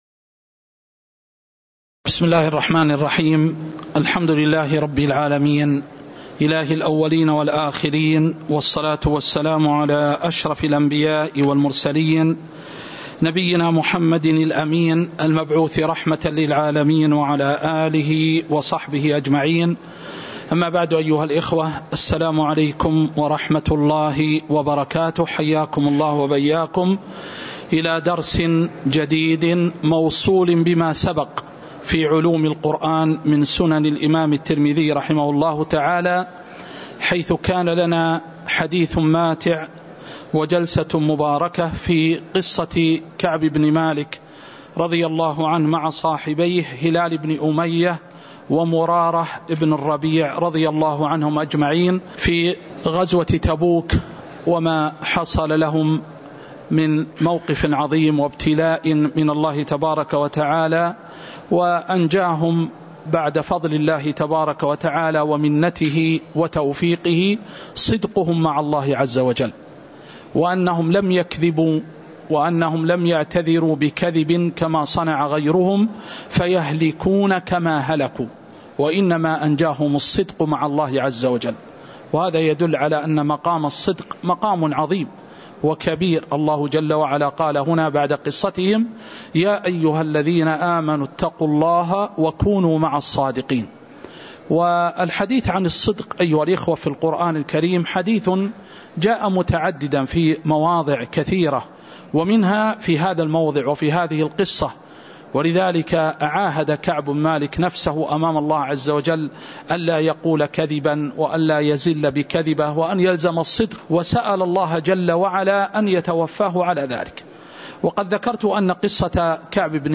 تاريخ النشر ١ جمادى الأولى ١٤٤٣ هـ المكان: المسجد النبوي الشيخ